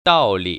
[dào‧li] 따오리  ▶